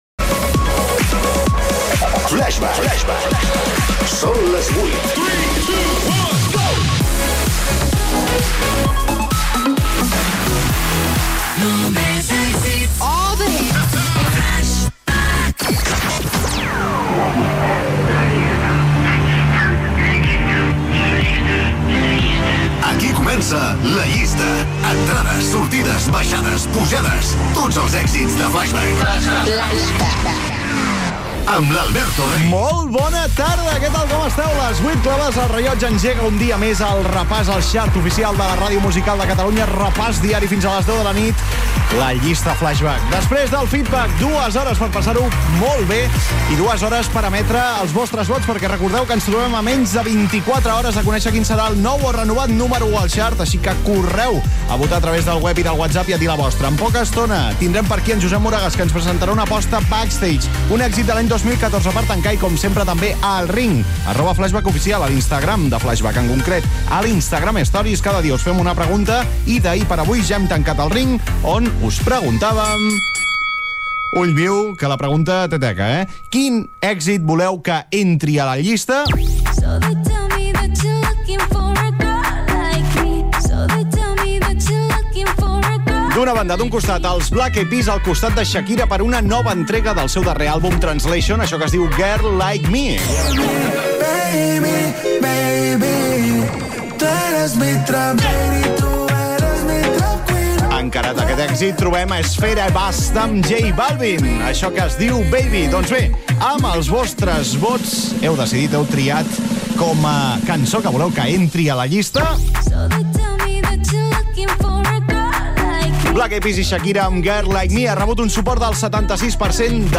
Indicatiu, hora, careta del programa, salutació i presentació inicial amb la pregunta feta a l'Instagram, nou tema que entra a la llista d'èxits i tema musical.
Musical